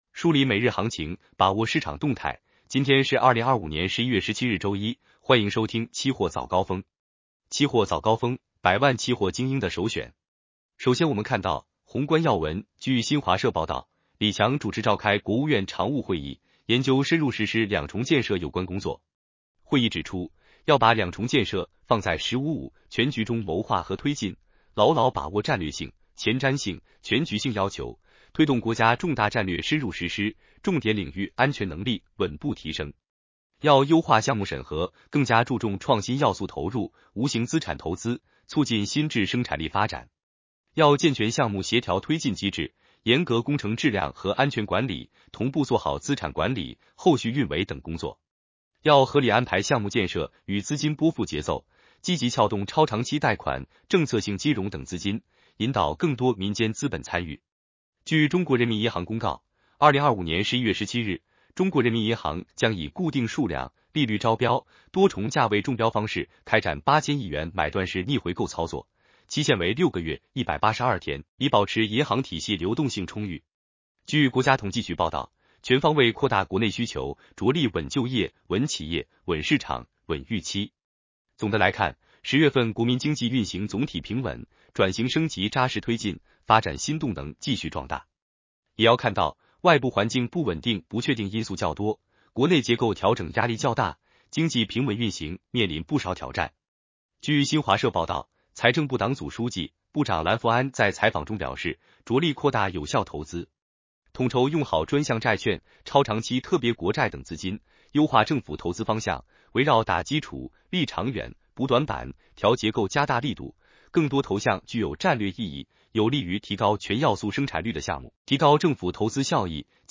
期货早高峰-音频版 男生普通话版 下载mp3 热点导读 1.